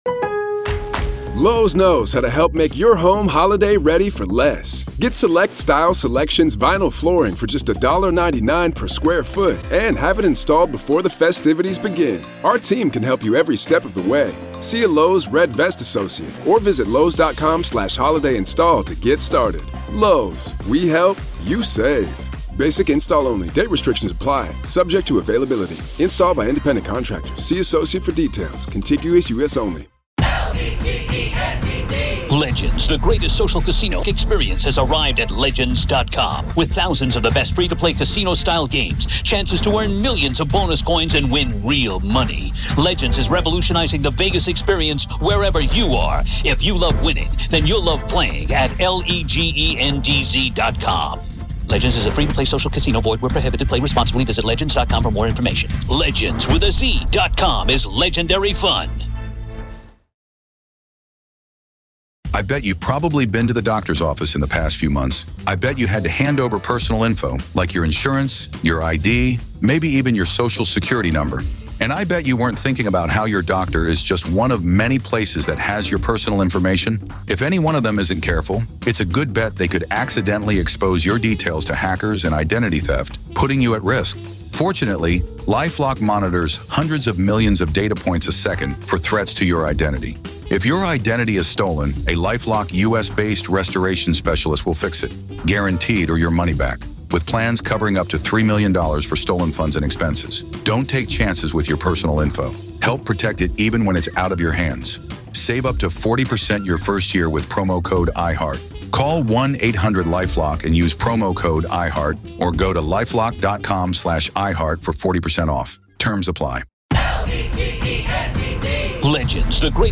RAW COURT AUDIO
Opening Statements